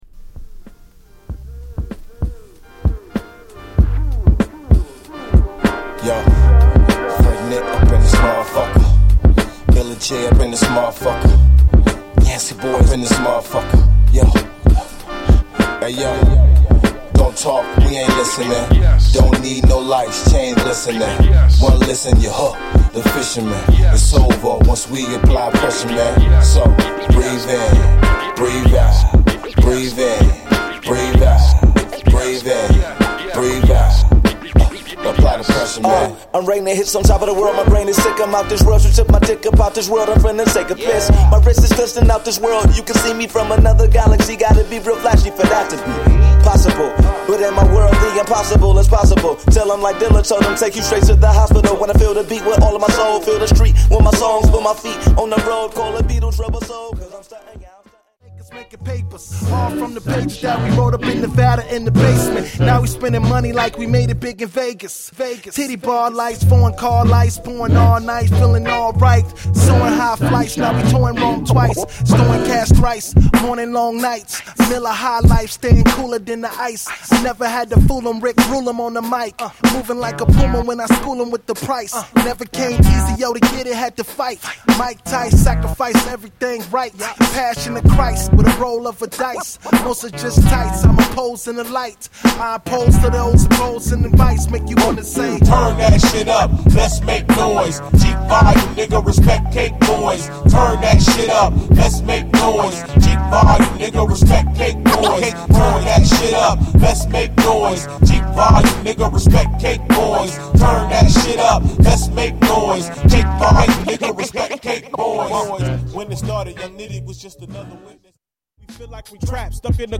Rap有り、歌モノ有りで大充実のアルバムに！